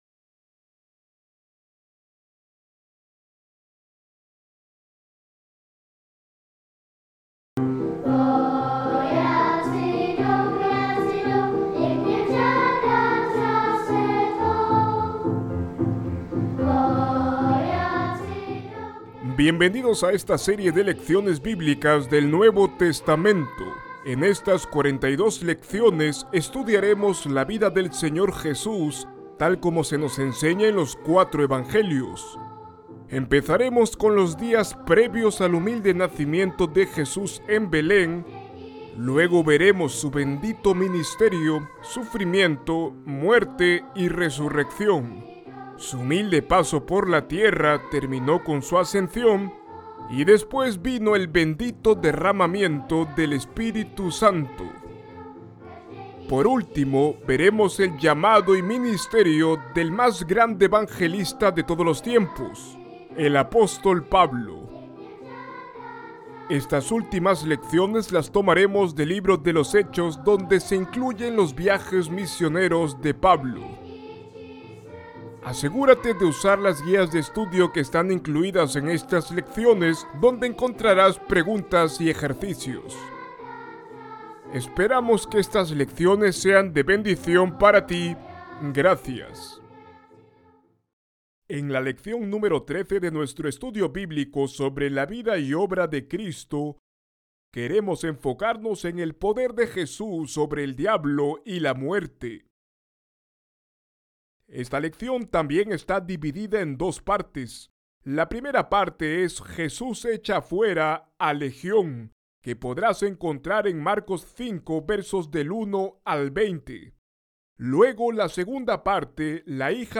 Cuando Jesús va a echar fuera a Legión, ellos le pidieron entrar en los cerdos, pensando que con ello escaparían, pero Jesús demuestra que tiene todo poder. Ver video Descargar video MP4 Escuchar lección Descargar audio en mp3 Ver transcripción en PDF Descargar transcripción en PDF Guia de Estudio